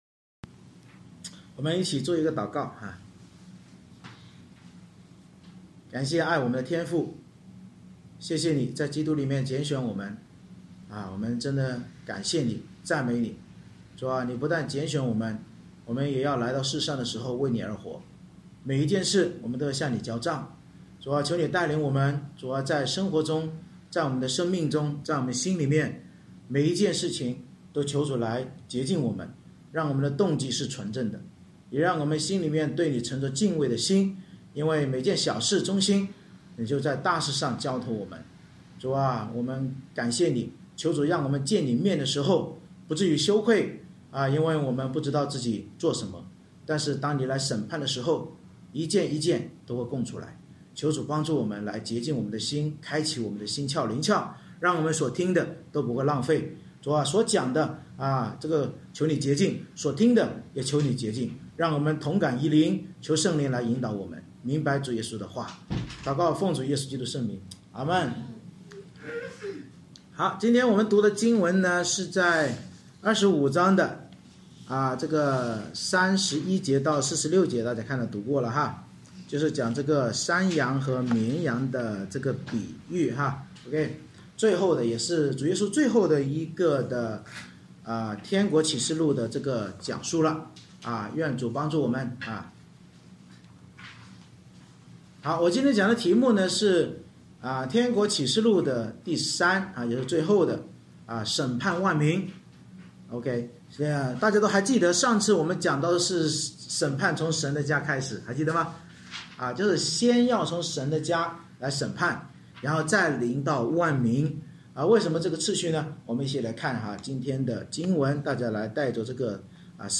马太福音25:31-46 Service Type: 主日崇拜 天国启示录末日审判的比喻警告我们当耶稣基督再临审判万民那一天，凡接待主耶稣与祂仆人的义人进入永生，凡不接待主耶稣与祂仆人的恶人必进入地狱。